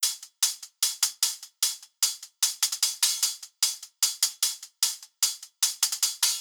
Closed Hats
Go Head Hi Hat.wav